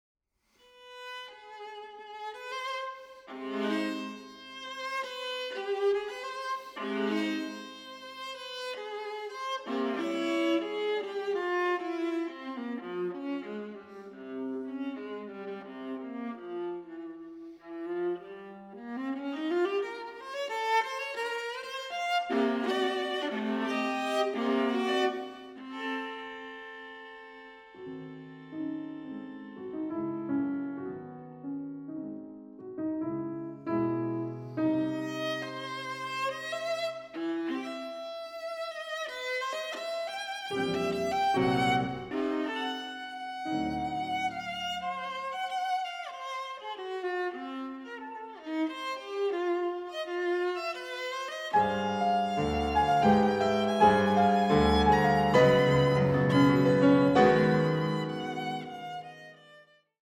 Viola
Klavier